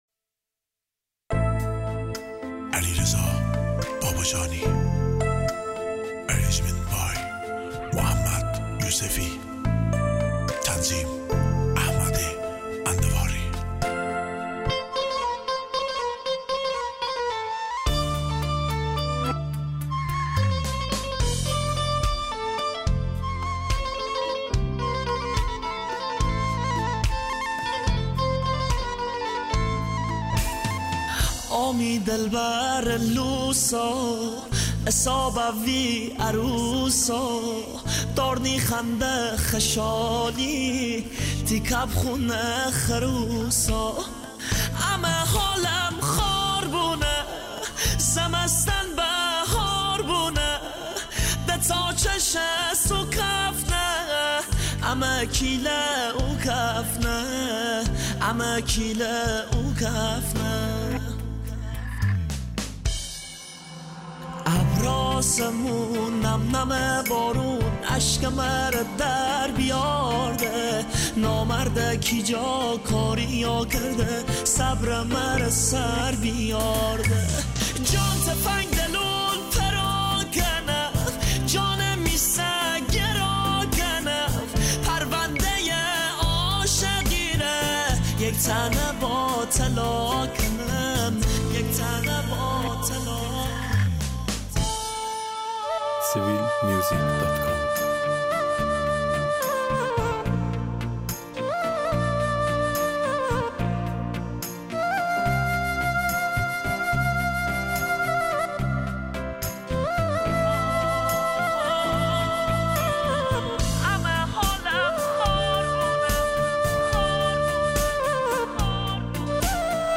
(آهنگ مازندرانی غمگین)